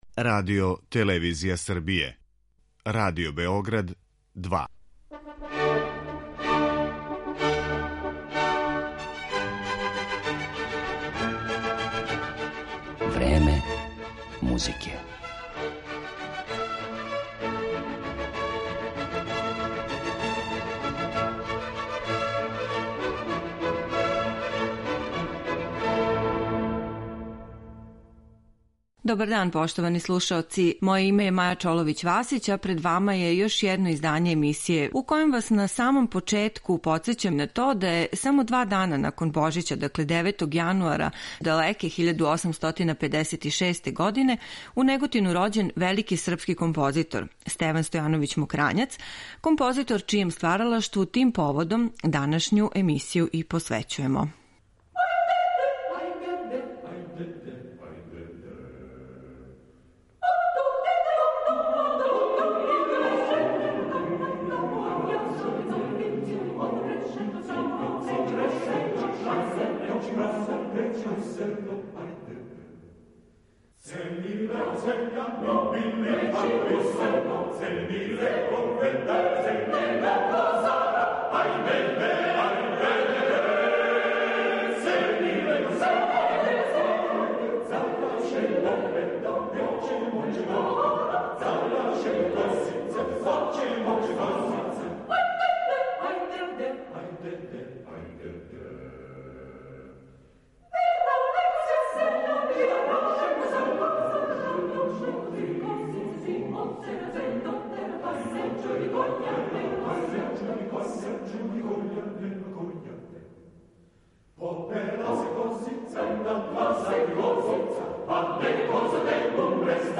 Овај дан обележићемо избором из његовог стваралаштва, у коме централно место припада хорској музици ‒ Руковетима и духовним композицијама, у извођењу врхунских домаћих извођача.